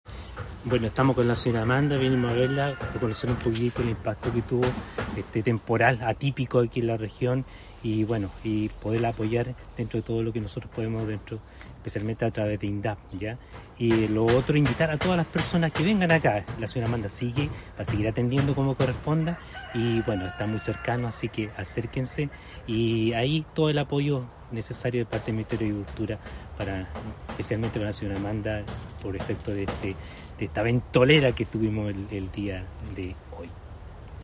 SEREMI de Agricultura Eugenio Ruiz